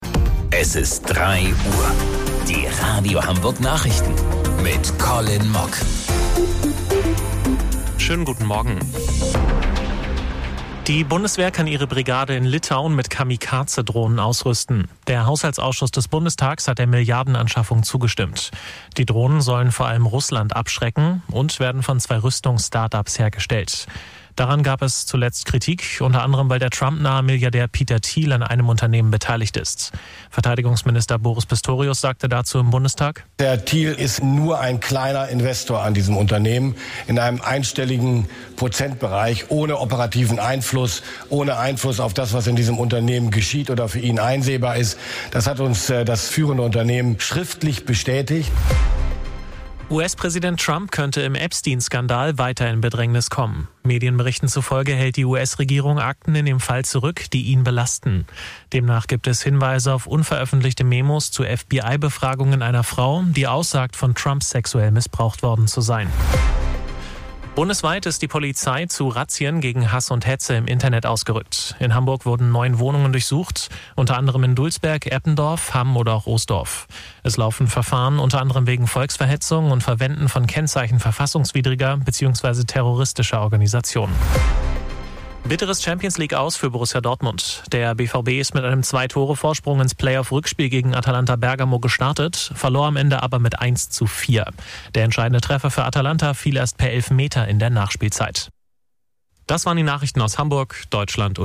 Radio Hamburg Nachrichten vom 27.02.2026 um 03 Uhr